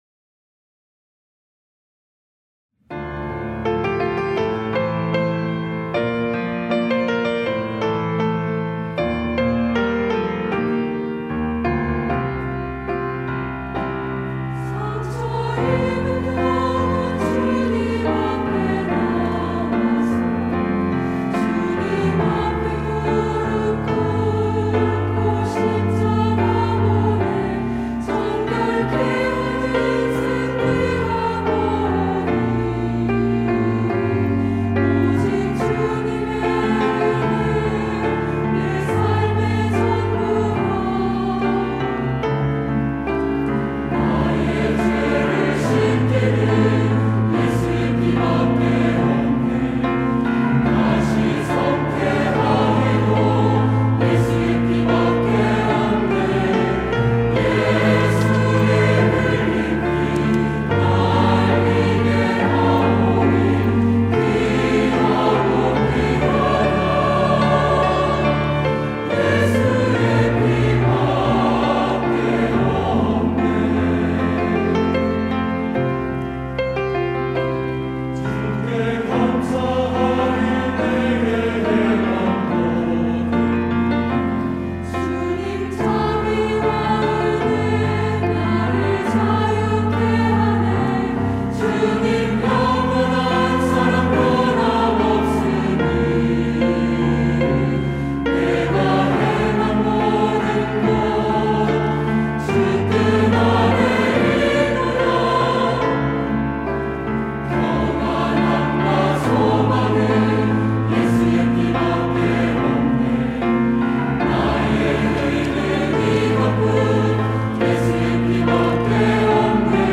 할렐루야(주일2부) - 나의 죄를 씻기는
찬양대